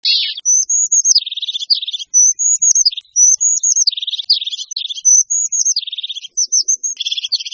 En cliquant ici vous entendrez le chant de la Mésange huppée